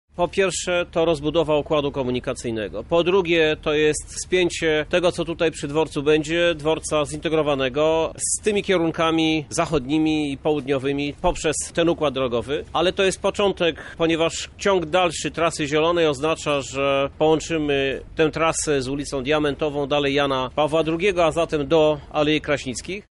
tłumaczy prezydent Lublina Krzysztof Żuk